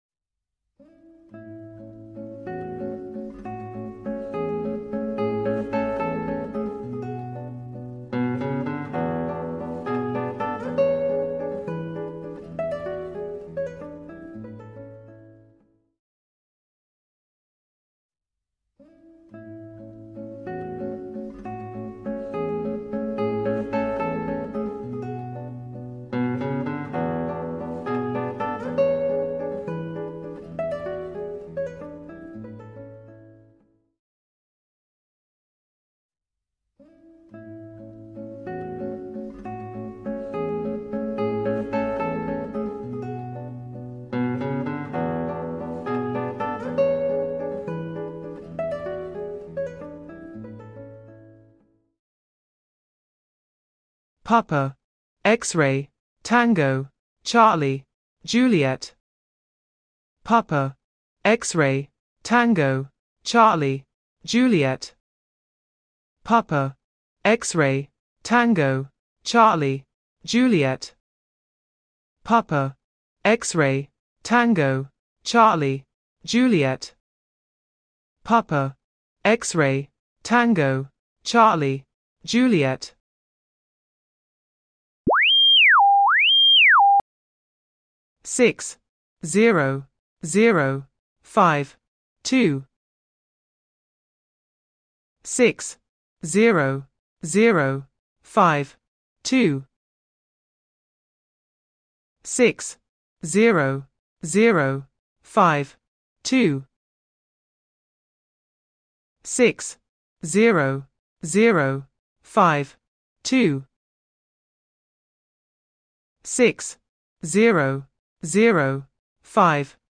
Howler signal: Indicates start of message Message body: 10 groups of 5 digits.
Howler signal: Indicates end of message Tech Stack Python Screenshots + Resources Generated OTP Full Broadcast